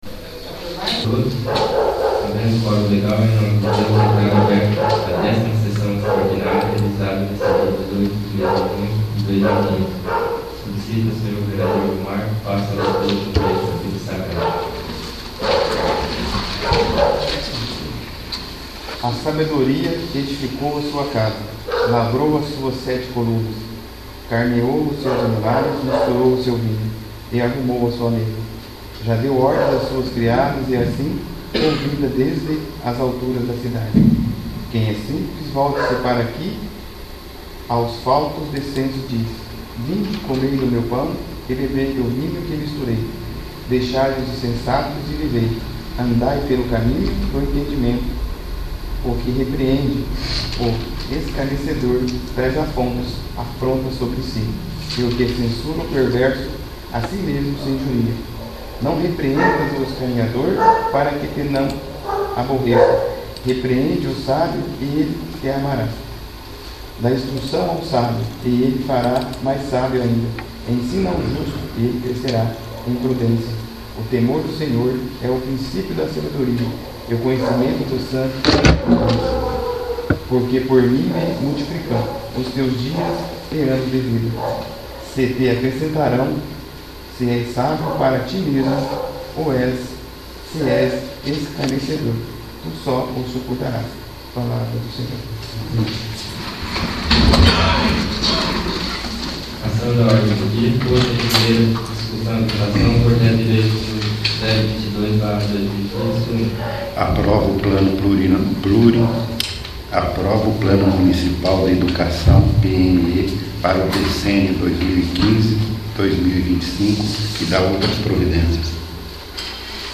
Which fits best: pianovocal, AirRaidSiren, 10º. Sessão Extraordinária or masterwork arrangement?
10º. Sessão Extraordinária